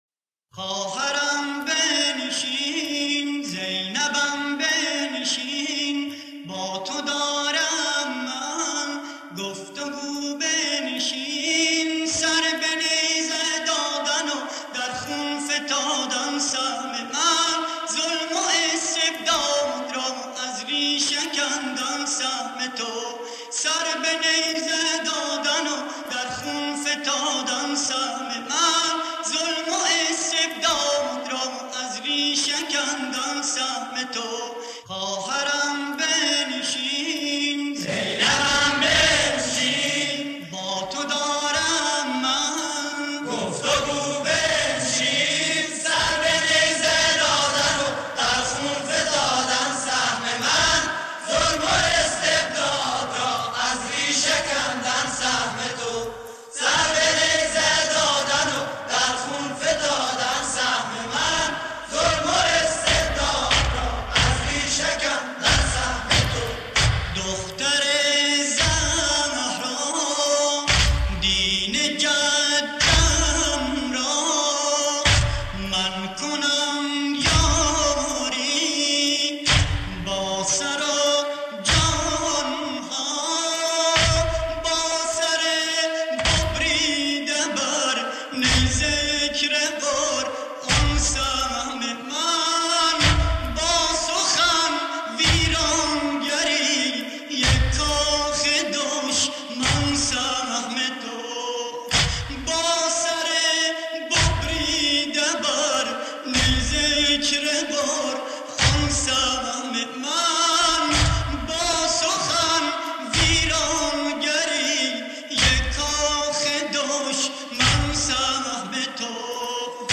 نوحه های محله فهادان یزد